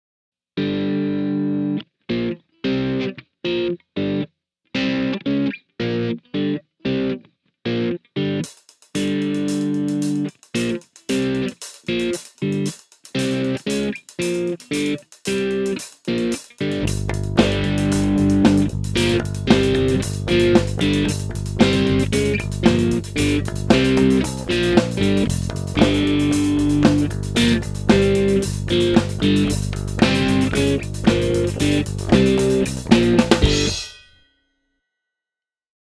So how would that riff sound backwards?